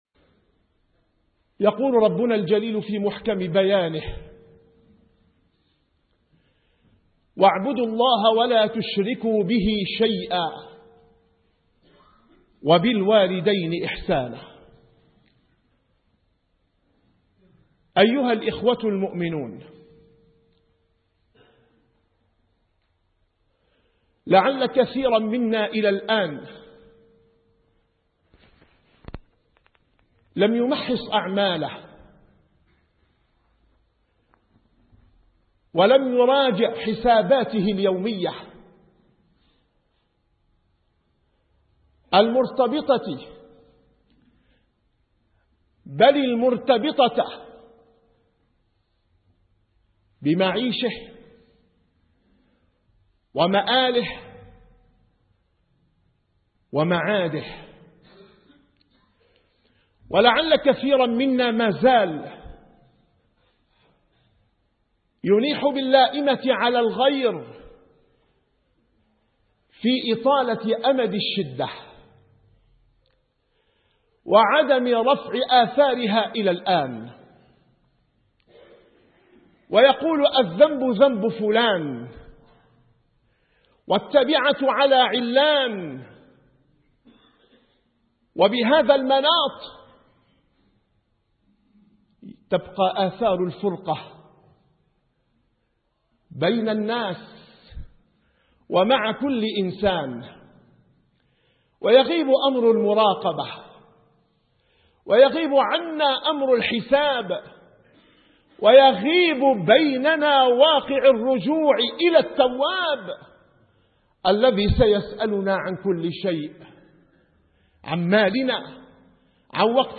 - الخطب -